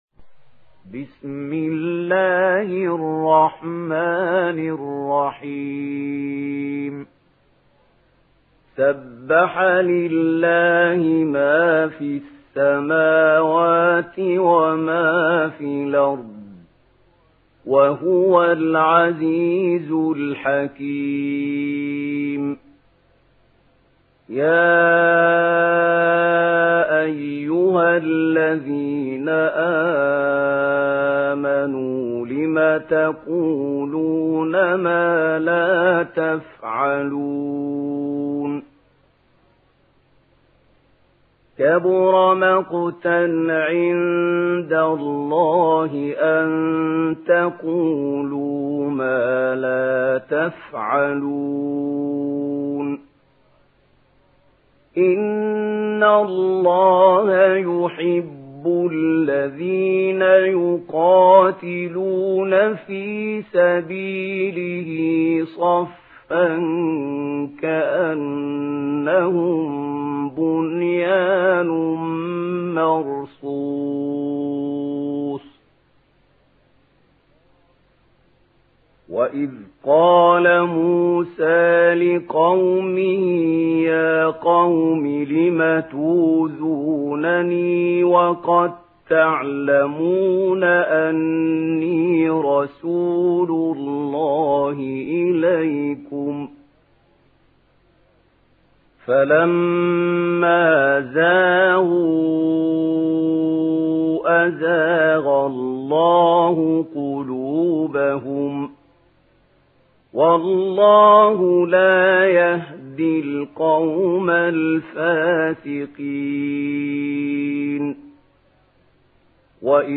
دانلود سوره الصف mp3 محمود خليل الحصري (روایت ورش)